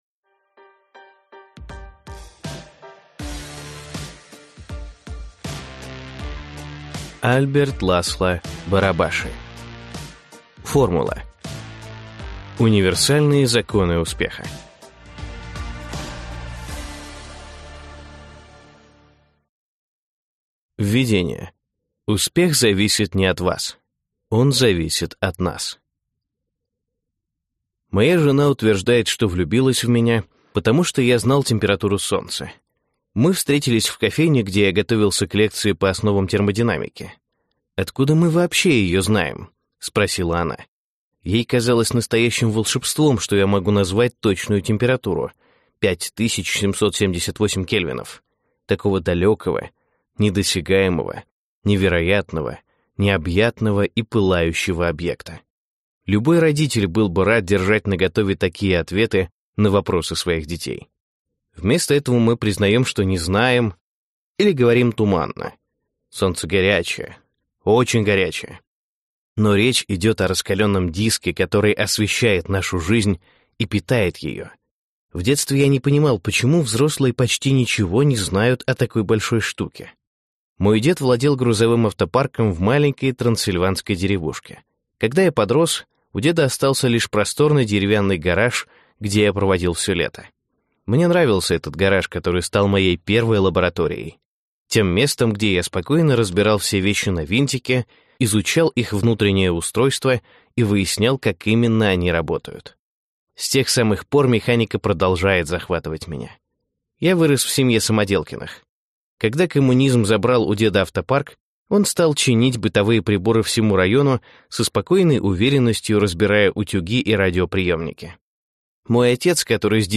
Аудиокнига Формула. Универсальные законы успеха | Библиотека аудиокниг
Прослушать и бесплатно скачать фрагмент аудиокниги